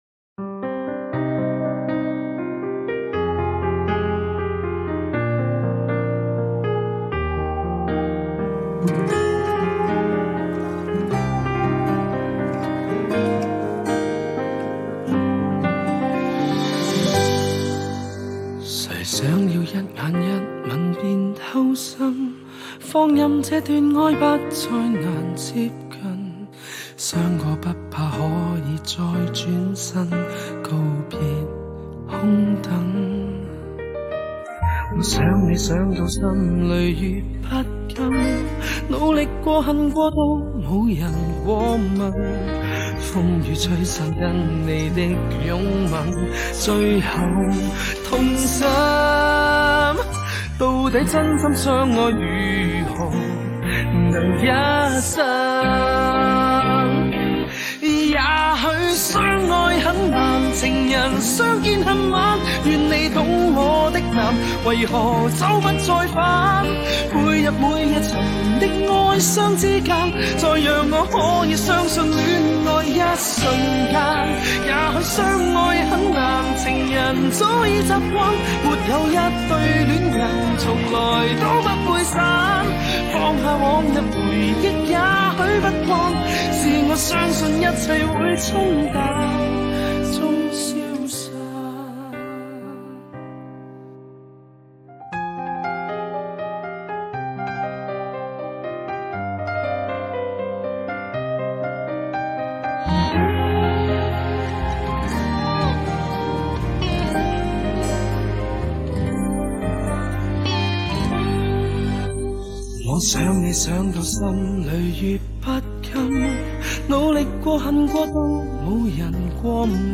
4/4 60以下
经典歌曲